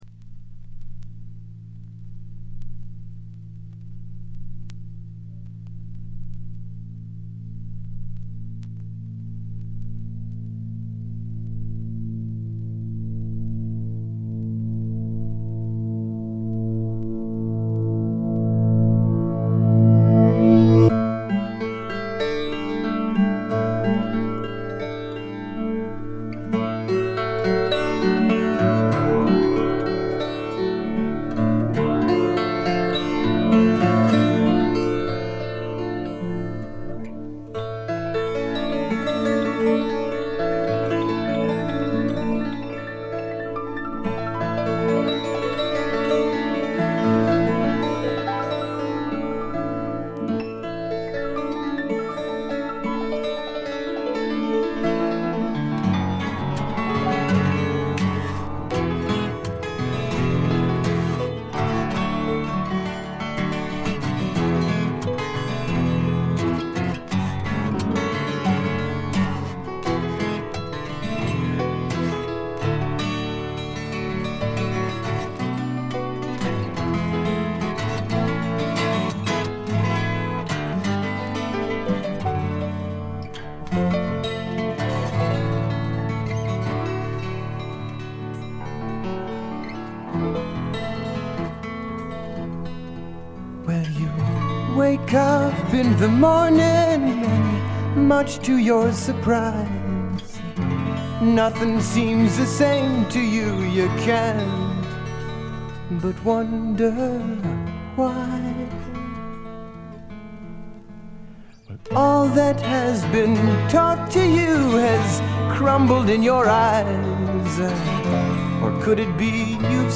いうまでもなく米国産アシッド・フォークの超有名レア盤にして永遠の至宝です。
異常に美しいアコースティック・ギターの響きとスペイシーなシンセが共鳴する異空間。
試聴曲は現品からの取り込み音源です。